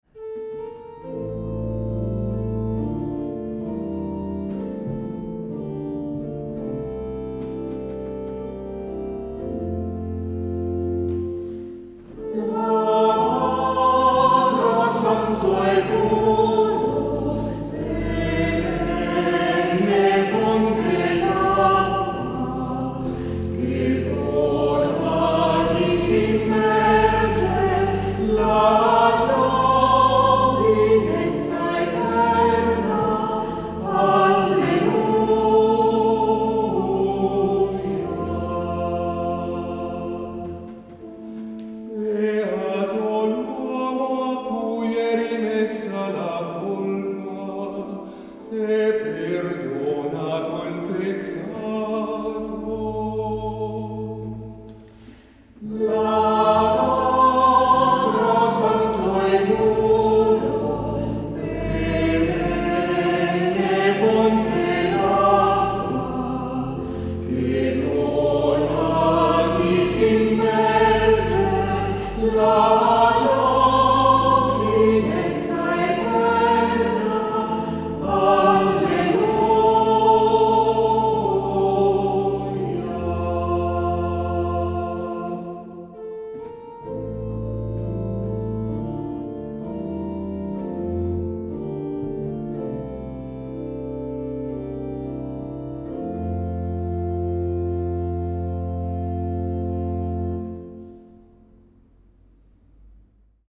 Terenzio Zardini, che ha intonato queste parole della liturgia, ha donato alla Chiesa milanese un gioiello per poter cantare il dono della giovinezza eterna a chi è stato immerso nel lavacro battesimale: si tratta di un canto intenso, nostalgico, ricco una letizia discreta e piena di speranza. Va eseguito con una certa morbidezza e scioltezza.